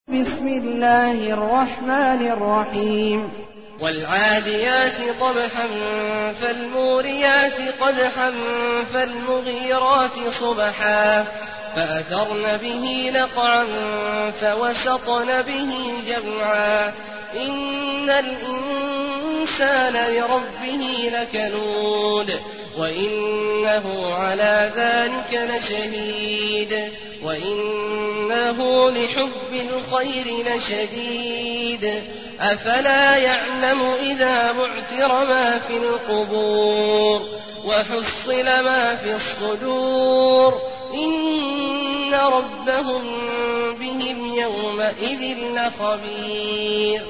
المكان: المسجد النبوي الشيخ: فضيلة الشيخ عبدالله الجهني فضيلة الشيخ عبدالله الجهني العاديات